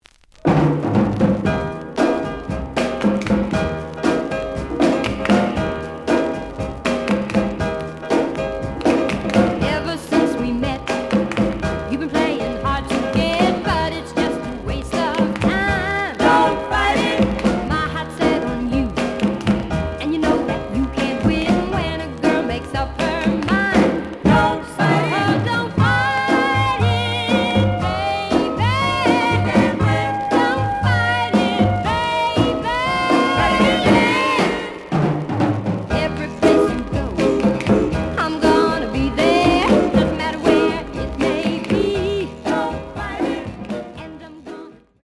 The audio sample is recorded from the actual item.
●Genre: Soul, 60's Soul
Some click noise on B side due to scratches.